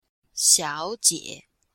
xiao3jie3-f.mp3